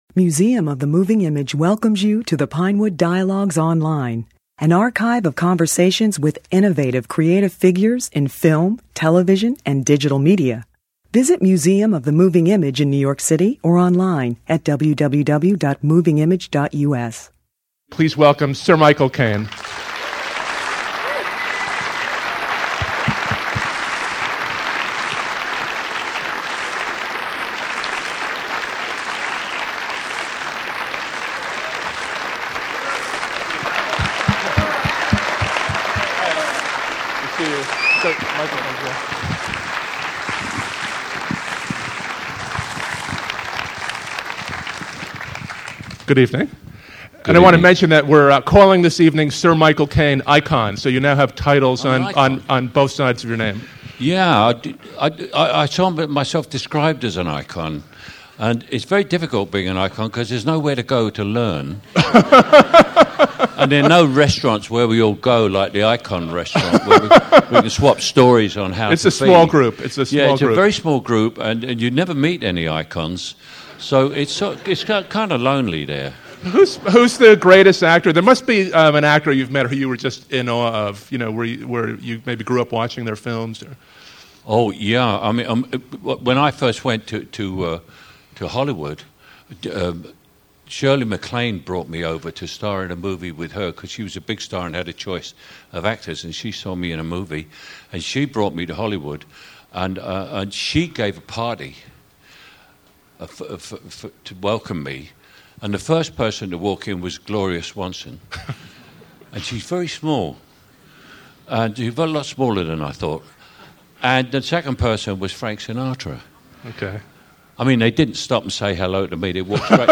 Michael Caine April 28, 2010 In this wide-ranging conversation, Michael Caine, who was born Maurice Micklewhite, reveals that he chose his new surname when he saw a poster for Humphrey Bogart's movie The Caine Mutiny .